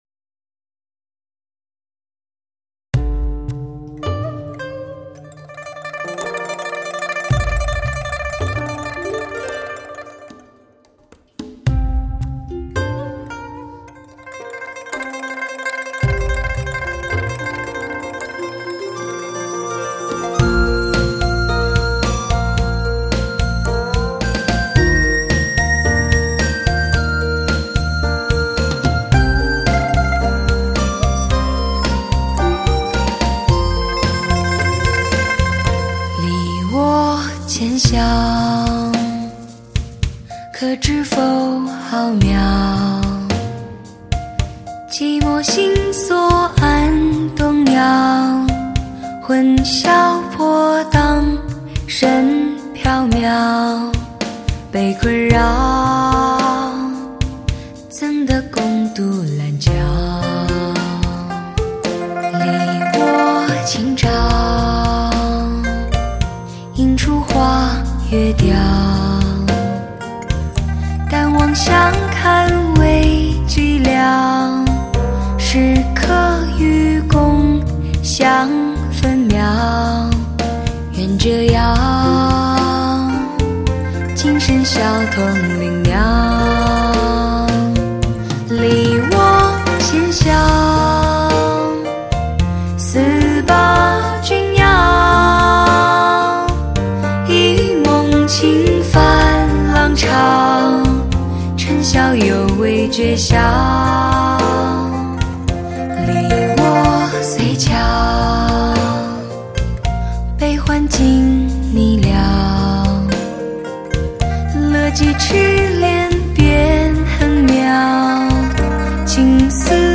磁性的丝质嗓音，诠释出浪漫感性的忧伤！